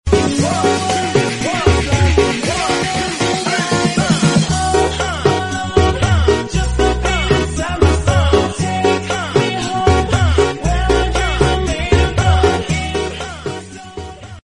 Breakfunk Slowed